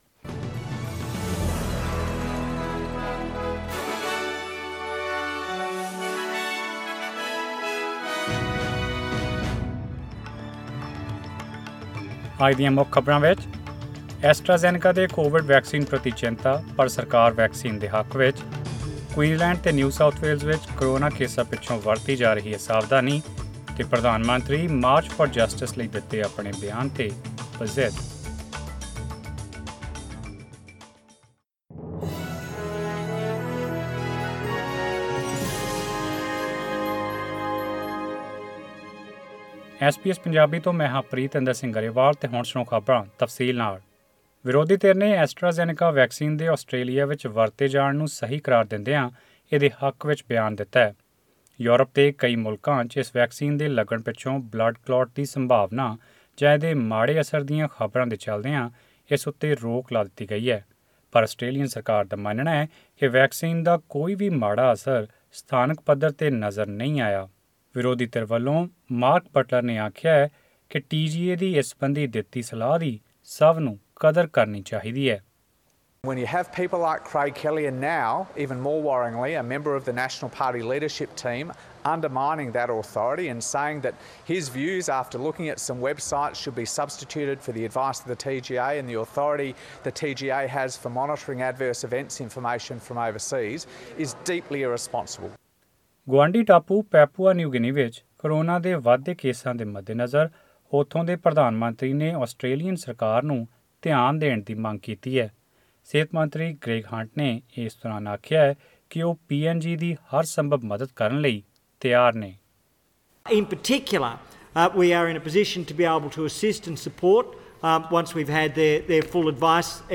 Australian News in Punjabi: Authorities back AstraZeneca vaccine despite safety concerns overseas